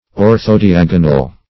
Search Result for " orthodiagonal" : The Collaborative International Dictionary of English v.0.48: Orthodiagonal \Or`tho*di*ag"o*nal\, n. [Ortho- + diagonal.]
orthodiagonal.mp3